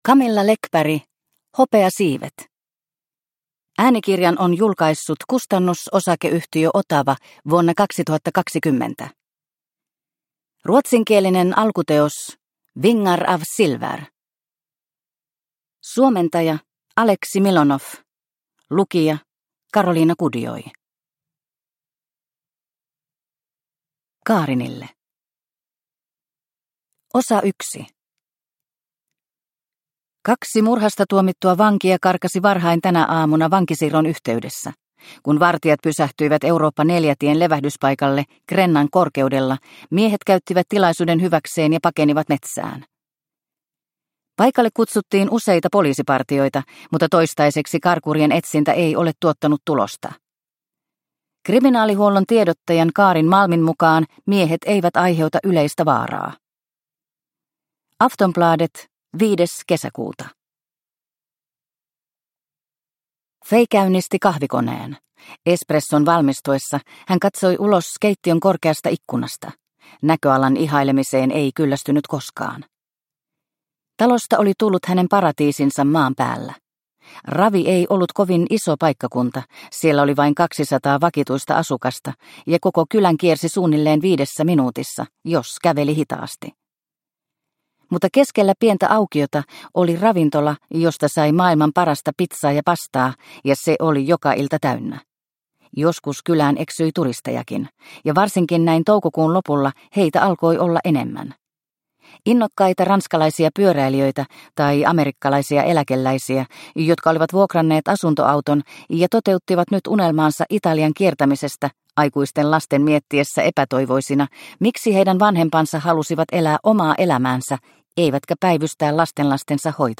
Hopeasiivet – Ljudbok – Laddas ner